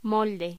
Locución: Molde
voz